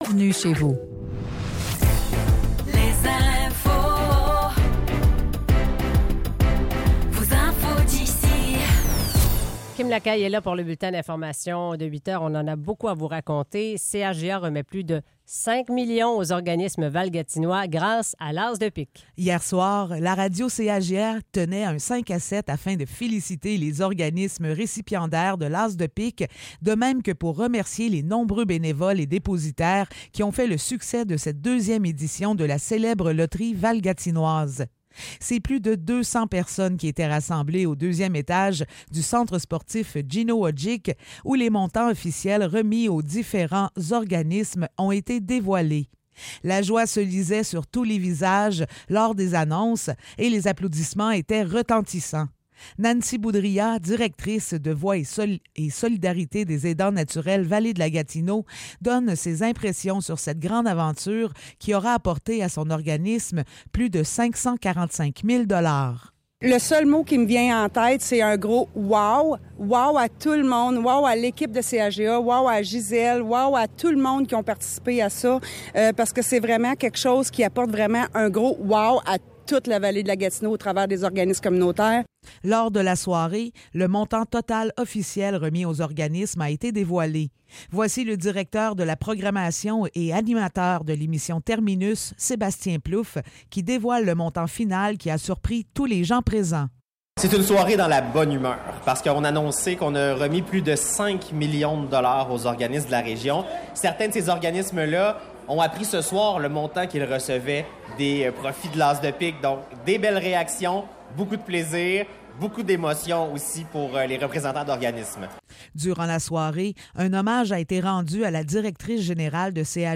Nouvelles locales - 13 septembre 2024 - 8 h